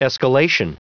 Prononciation du mot escalation en anglais (fichier audio)